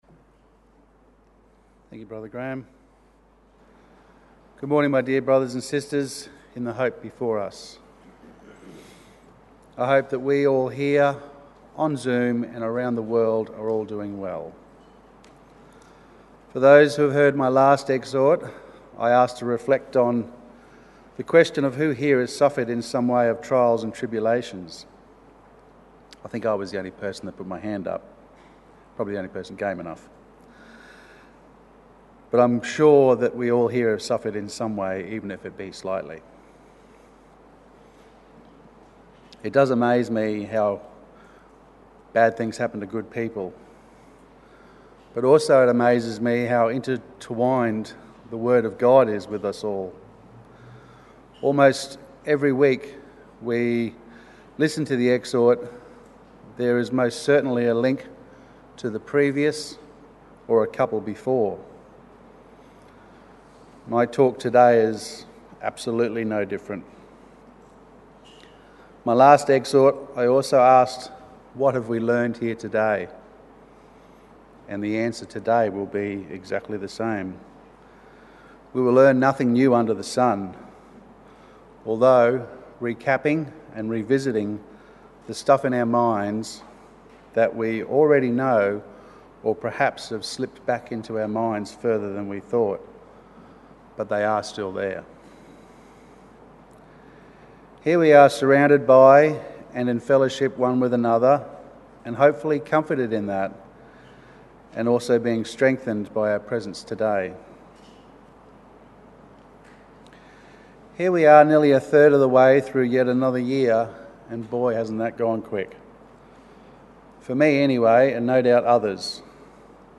Exhortation - Reflections - Know Your Bible